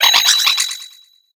Cri de Wimessir femelle dans Pokémon HOME.